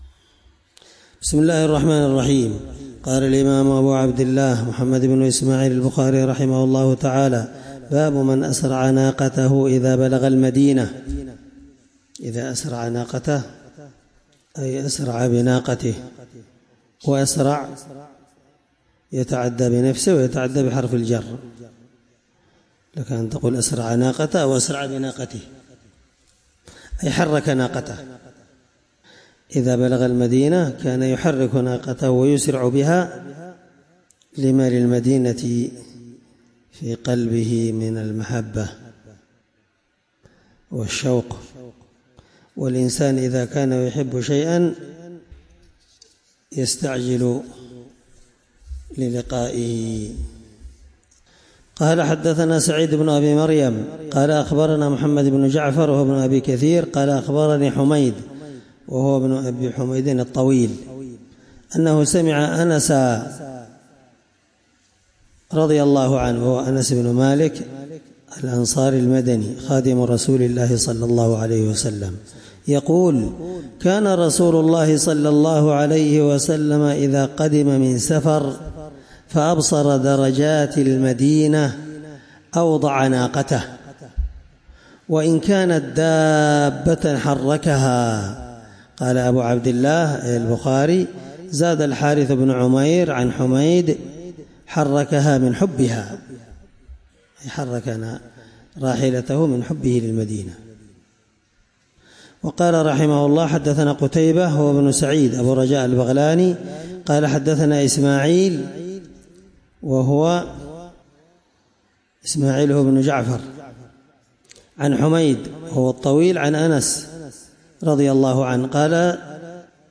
الدرس 12من شرح كتاب العمرة حديث رقم(1802)من صحيح البخاري